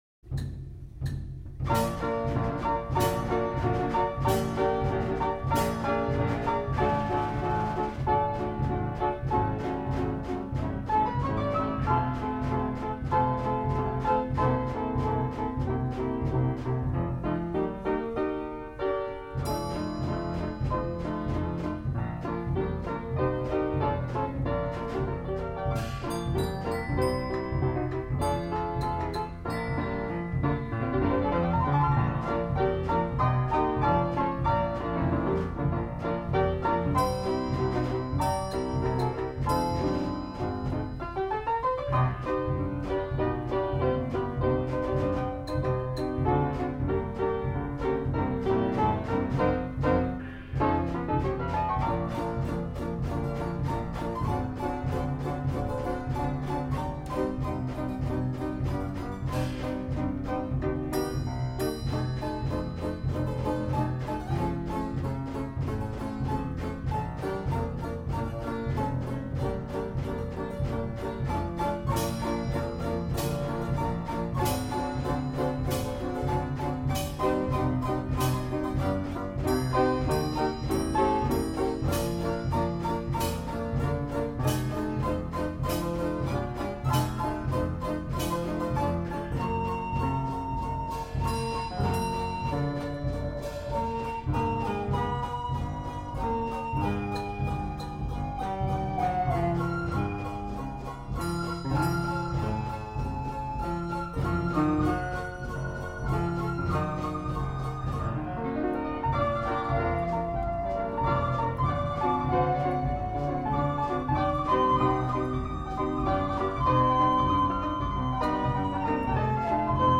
Hupfeld-Sinfonie-Jazz-Orchestrion, Ludwig Hupfeld–Gebr.